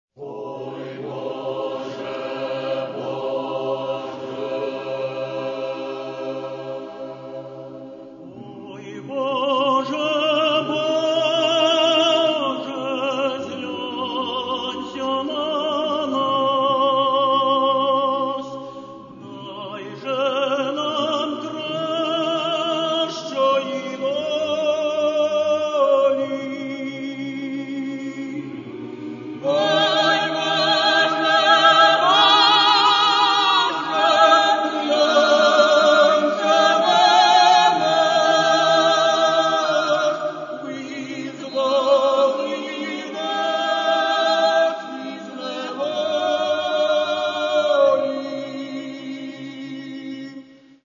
Каталог -> Народна -> Солоспіви та хори
У співі хору – і міцна сила, і широкий простір.